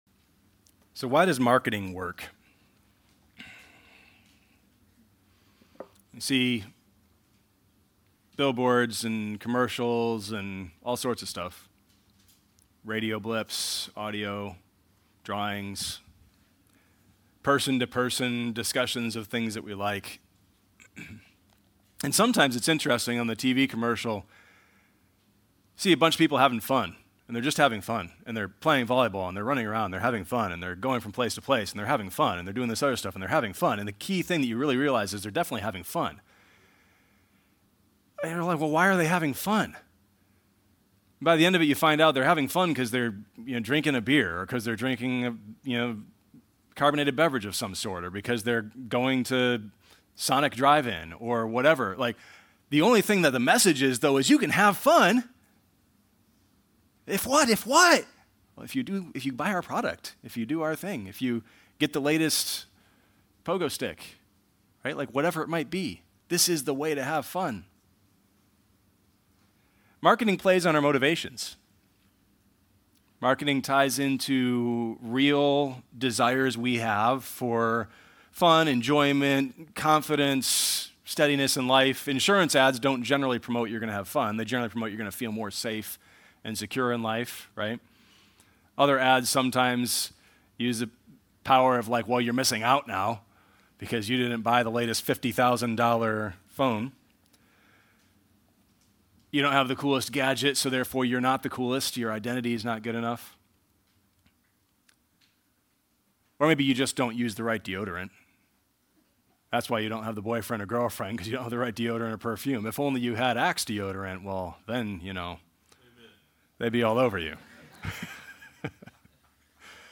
Sermons - Connection Fellowship